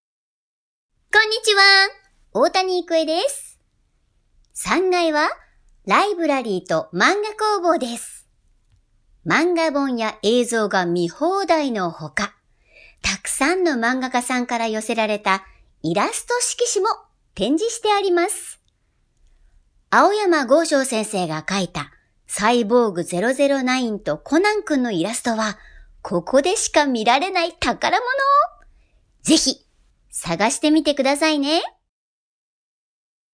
案内人：大谷育江さん
「ポケットモンスター」ピカチュウ役